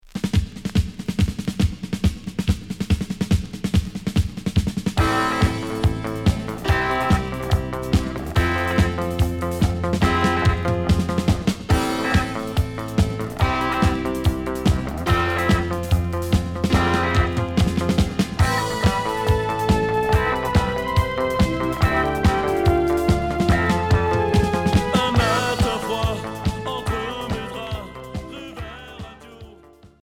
Rock new wave Premier 45t retour à l'accueil